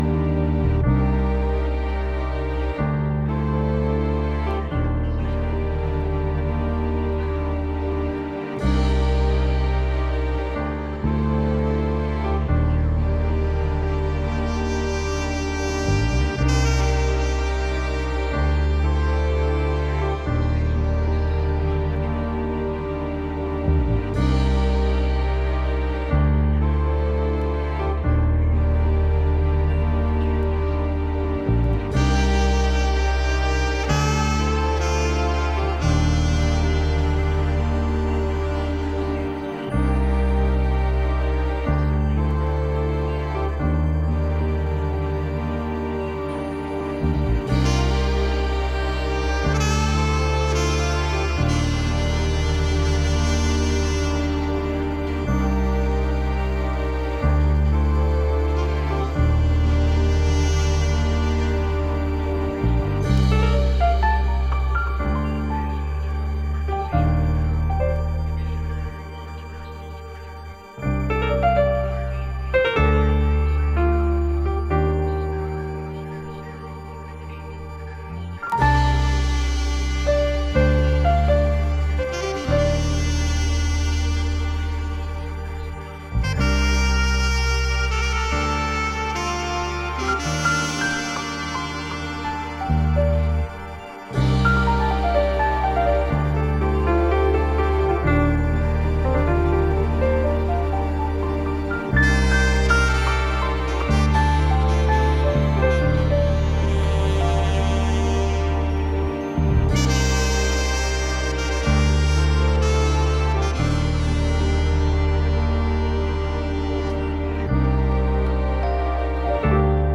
Breakbeat Disco / Balearic